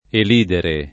vai all'elenco alfabetico delle voci ingrandisci il carattere 100% rimpicciolisci il carattere stampa invia tramite posta elettronica codividi su Facebook elidere [ el & dere ] v.; elido [ el & do ] — pass. rem. elisi [ el &@ i ]; part. pass. eliso [ el &@ o ]